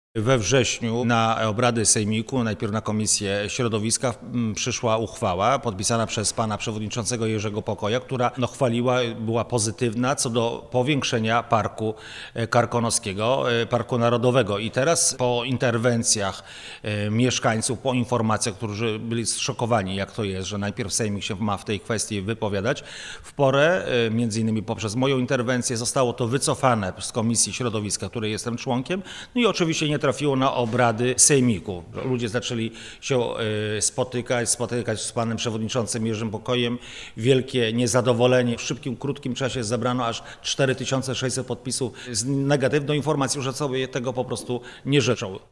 – Po interwencjach mieszkańców w porę wycofano uchwałę z Komisji Środowiska – mówi radny Andrzej Kredkowski, wiceprzewodniczący klubu PiS w Sejmiku Województwa Dolnośląskiego.